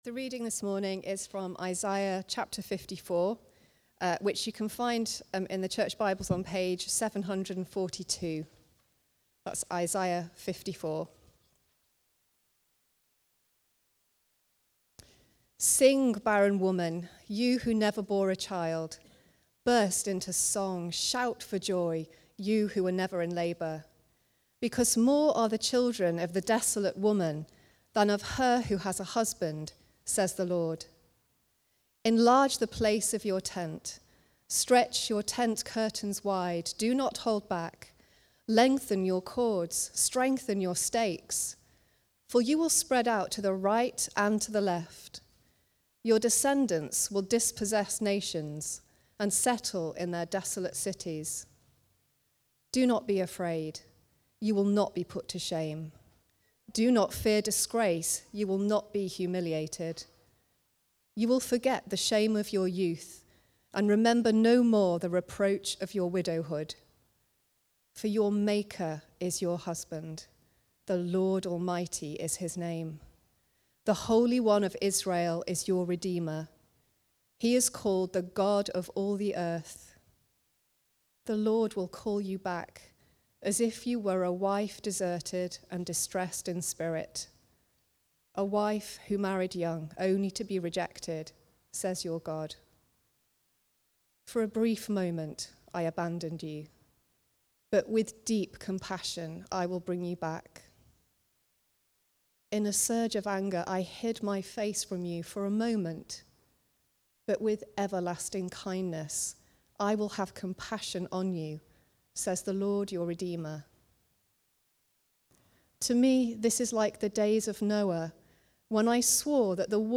Preaching
Vision Check-up (Isaiah 54) from the series A Vision for 2024. Recorded at Woodstock Road Baptist Church on 01 September 2024.